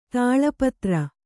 ♪ tāḷa patra